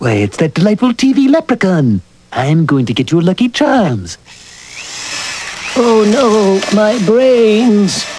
Voiced by Dan Castellaneta